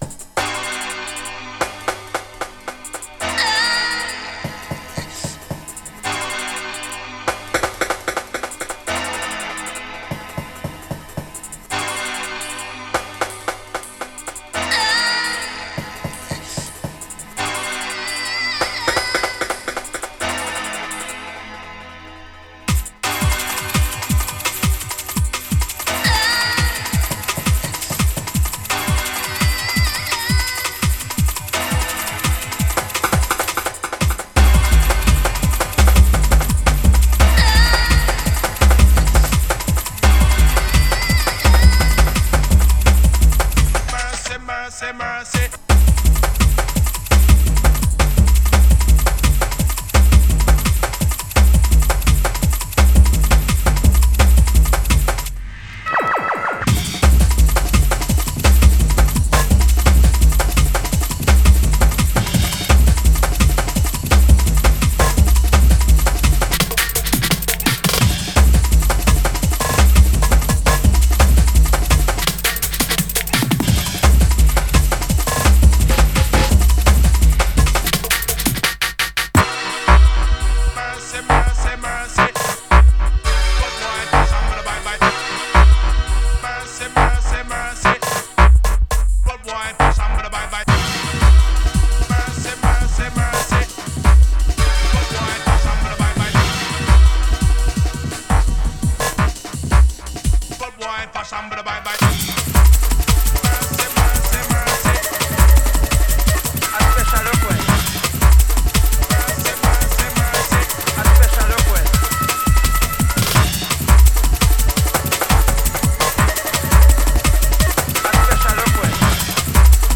Style: Classic ragga jungle sounds